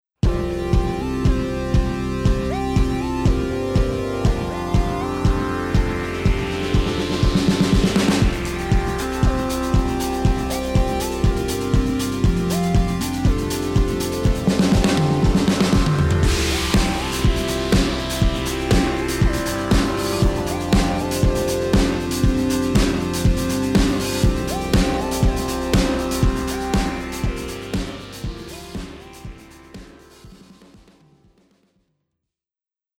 BigSky-Wet-Drums-1.mp3